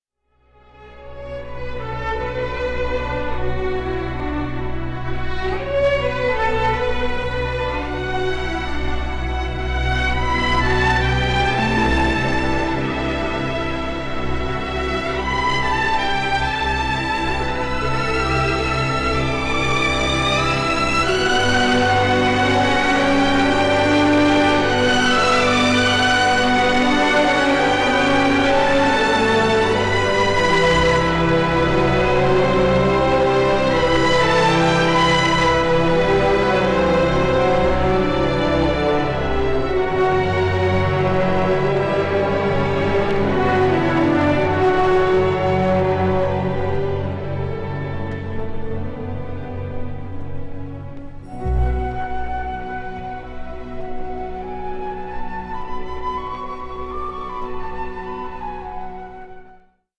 possente colonna musicale
Original track music